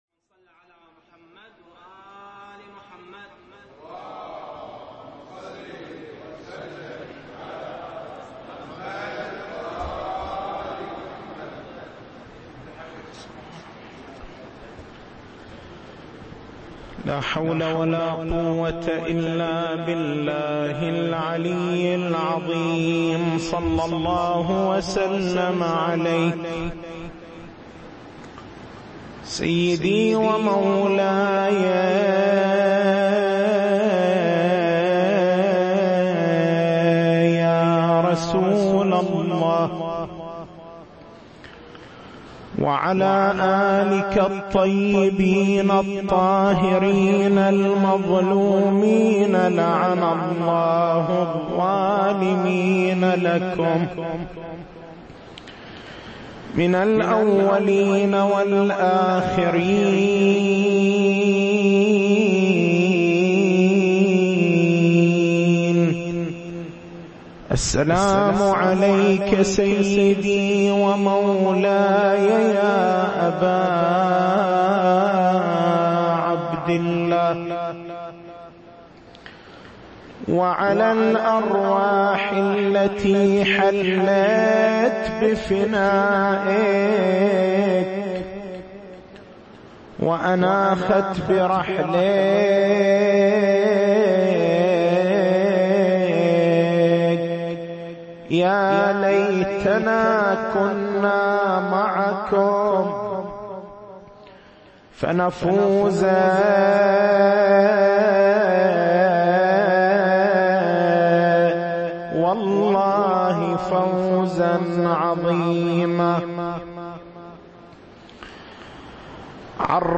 تاريخ المحاضرة: 03/09/1435 محور البحث: ما هو سرّ تأكيد الروايات الشريفة على الإكثار من قراءة القرآن الكريم في شهر رمضان المبارك؟